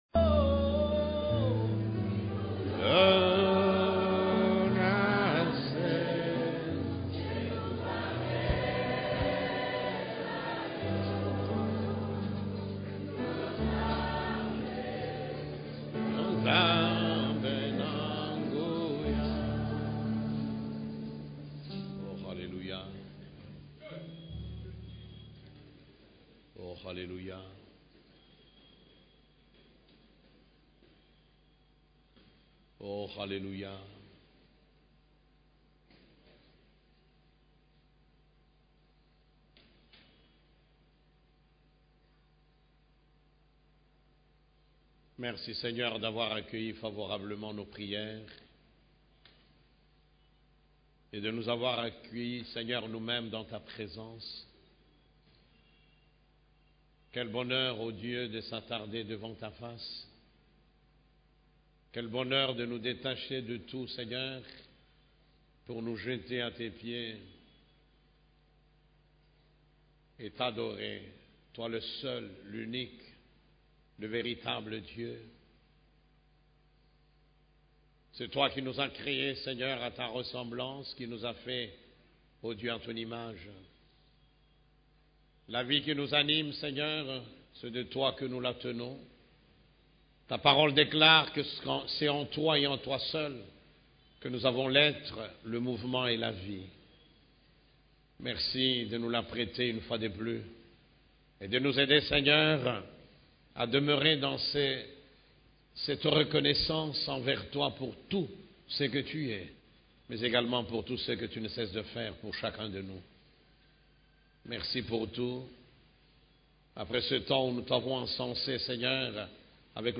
CEF la Borne, Culte du Dimanche, Dans quelle direction avancée